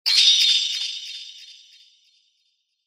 シャキーンの音は、爽快感と活気を感じさせる瞬間を演出する効果音です。思わずテンションが上がり、ポーズを決めた時のシーンにぴったりです。